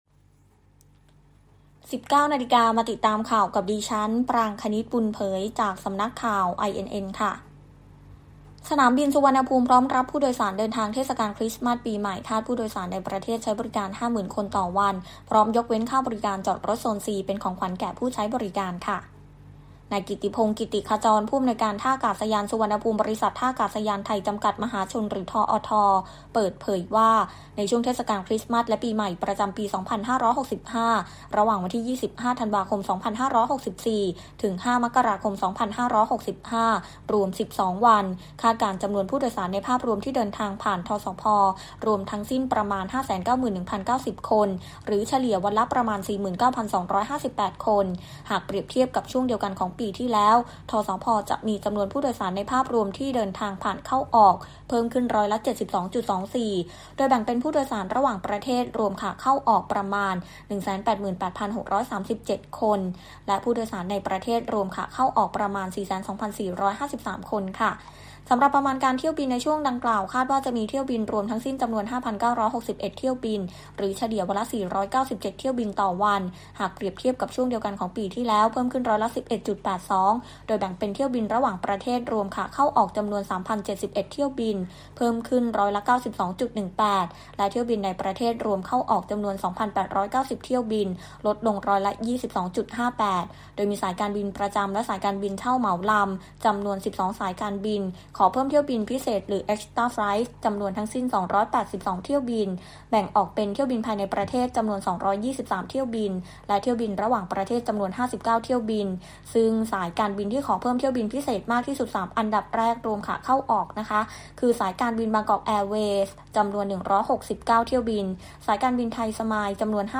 คลิปข่าวทั่วไป